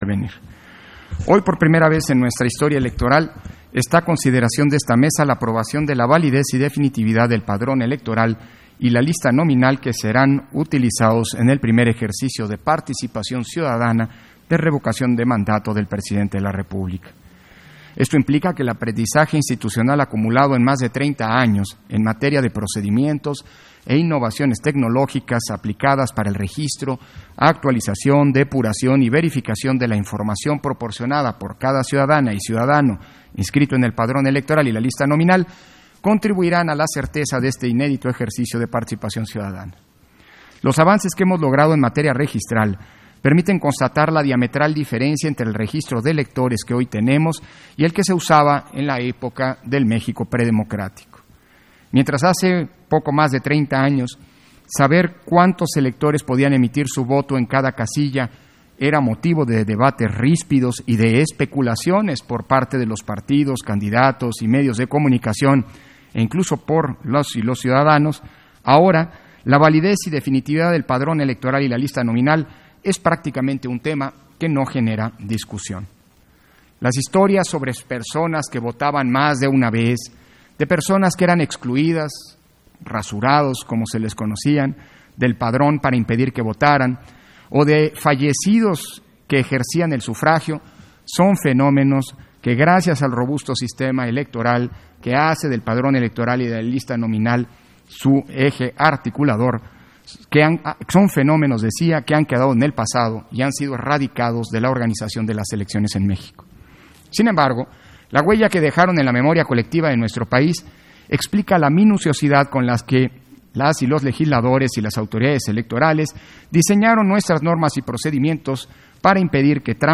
230322_AUDIO_INTERVENCIÓN-CONSEJERO-PDTE.-CÓRDOVA-PUNTO-7-SESIÓN-EXT.